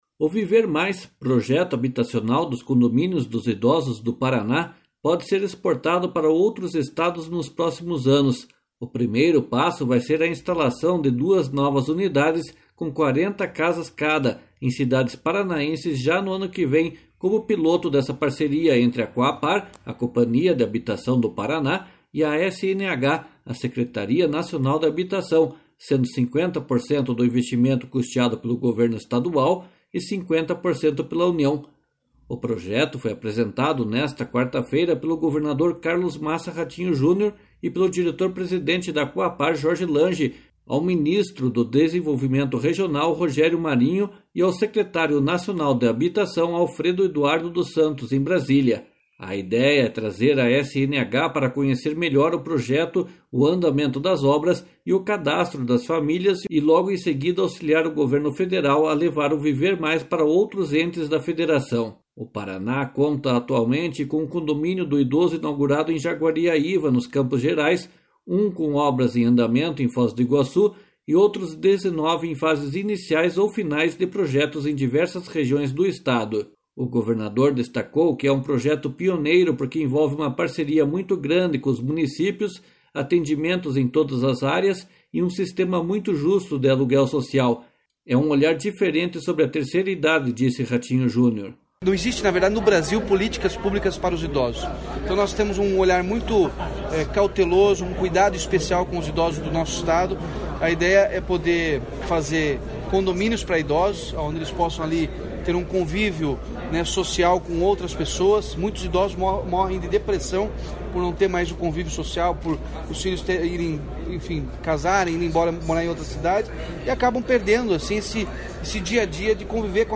//SONORA RATINHO JUNIOR//
//SONORA JORGE LANGE//